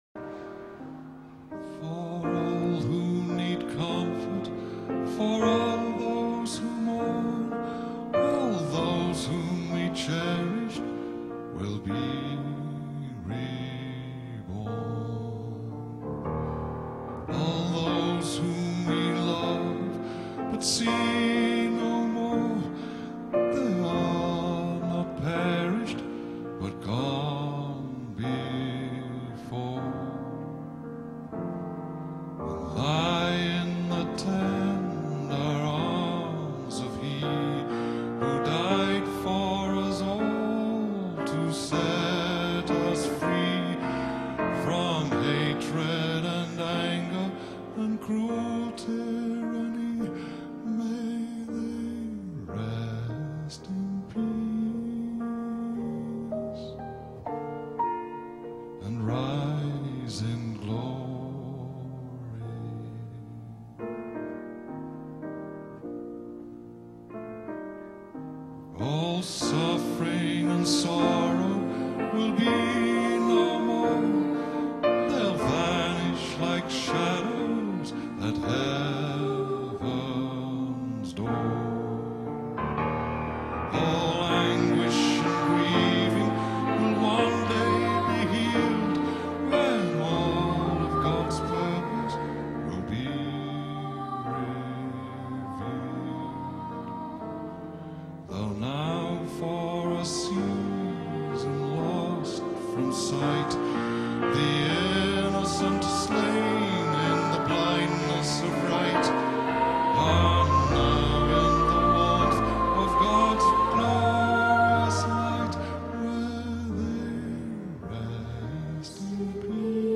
and backed up by the choral group